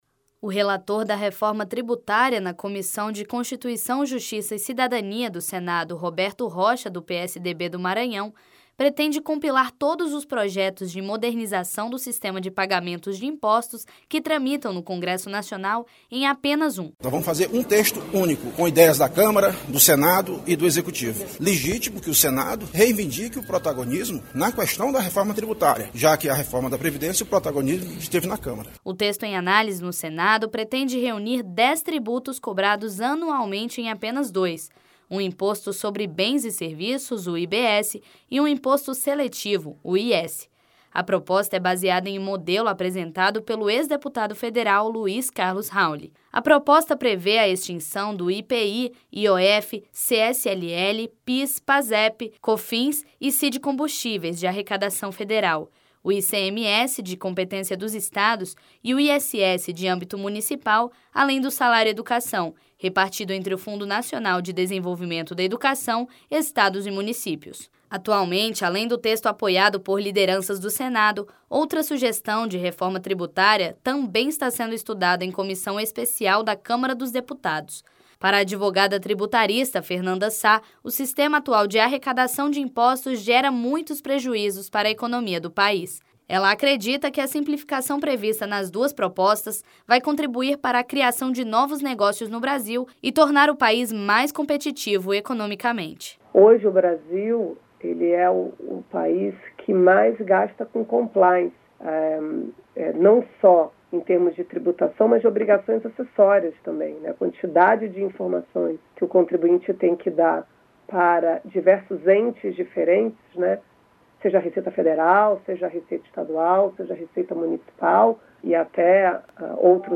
*Reportagem com aúdio